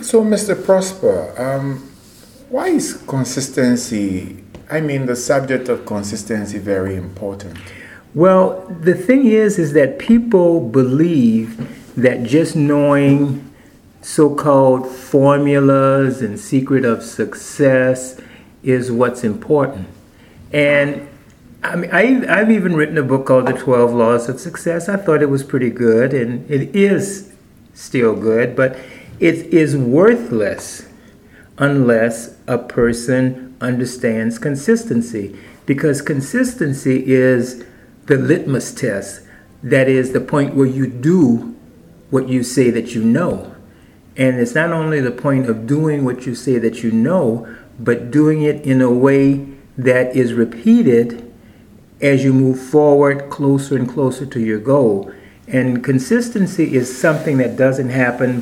60-second-Sampler-of-Consistency-Interview.mp3